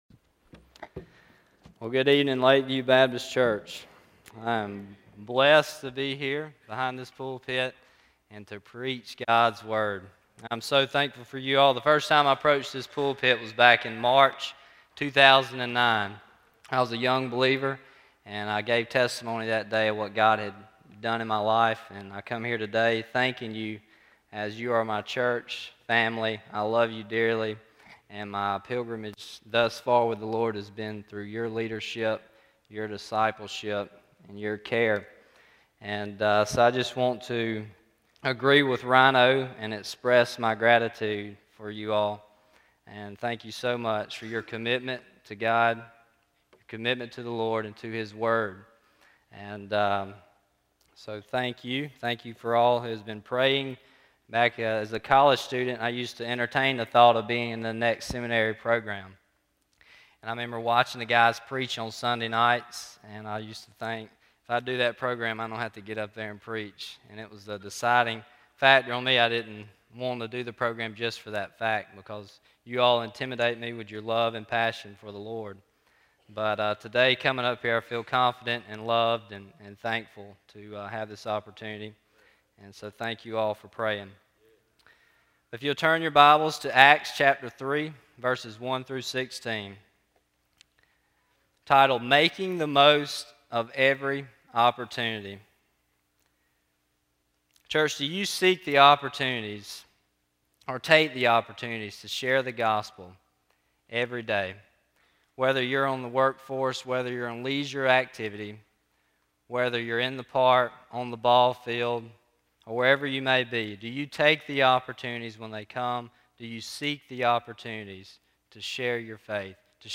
Intern Sermon Date